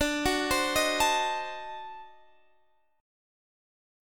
Listen to D7b9 strummed